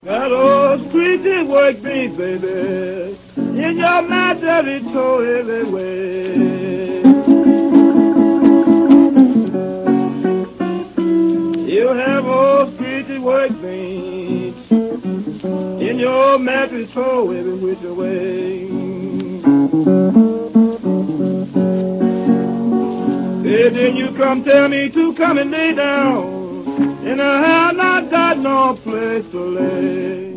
партия второй гитары